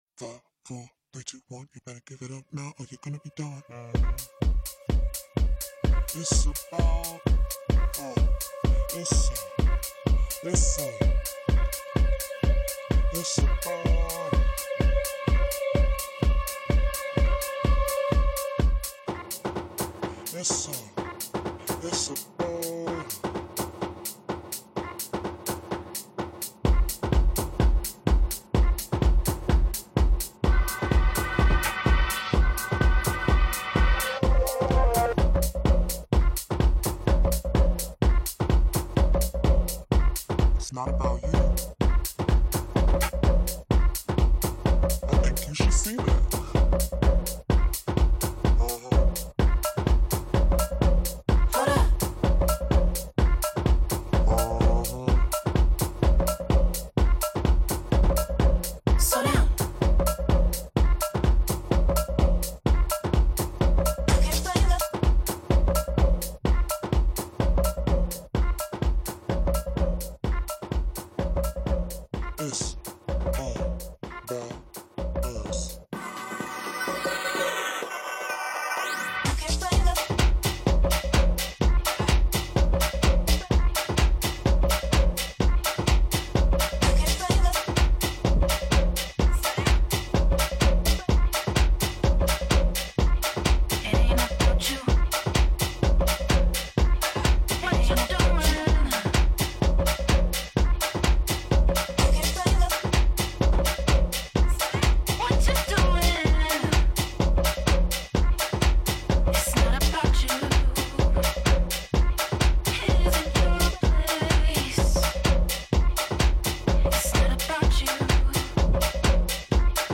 DJ Mixes and Radio